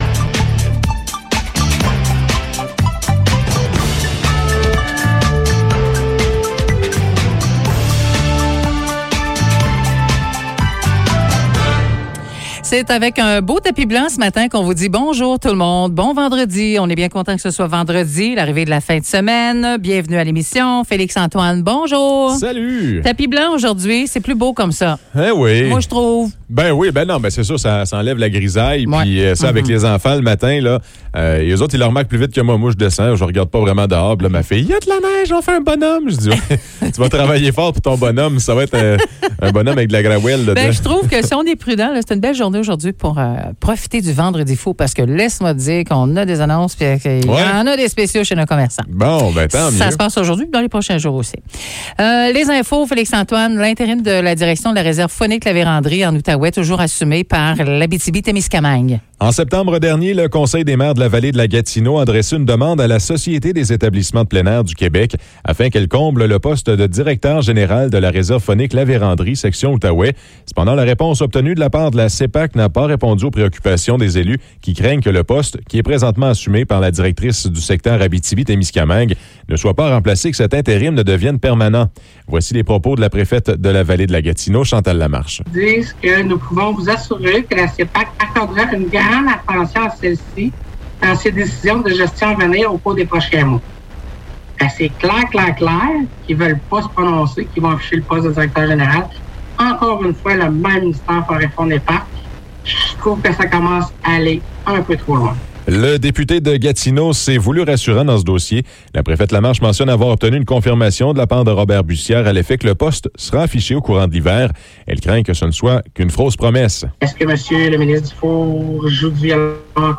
Nouvelles locales - 26 novembre 2021 - 9 h